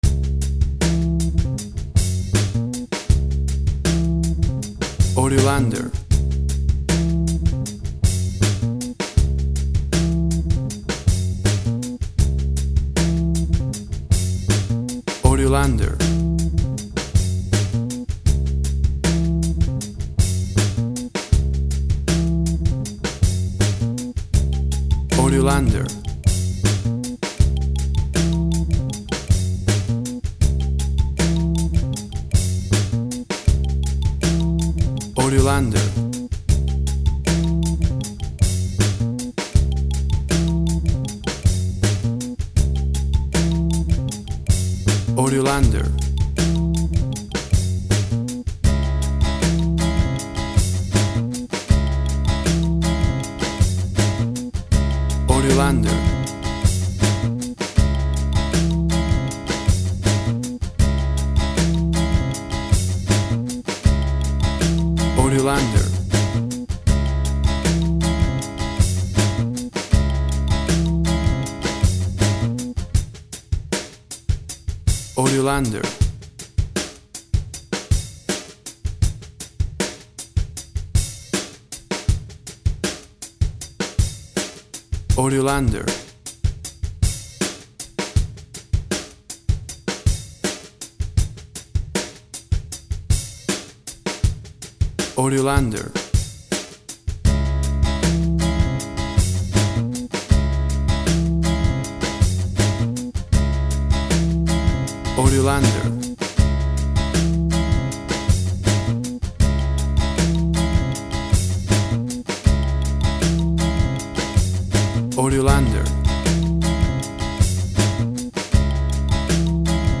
WAV Sample Rate 16-Bit Stereo, 44.1 kHz
Tempo (BPM) 78